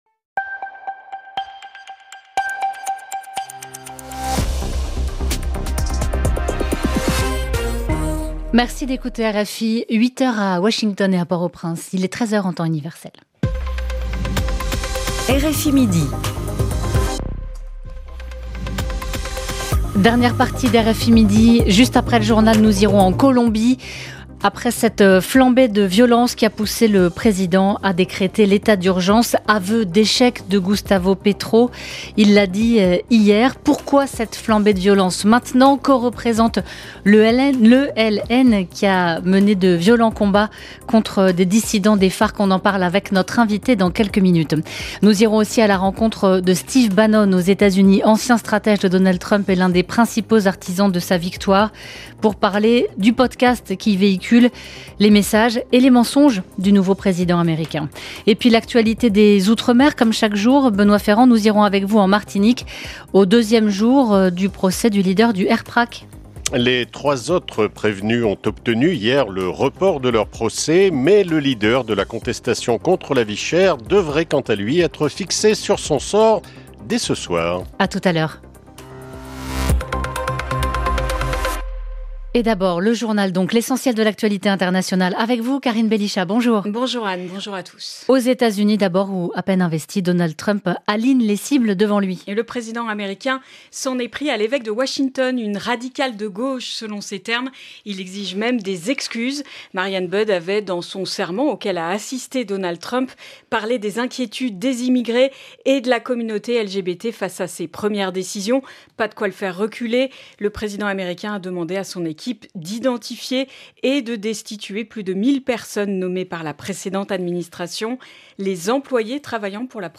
C’est pour cet auditoire que, chaque jour, RFI consacre un reportage, ou une interview, spécifiquement consacré à Haïti.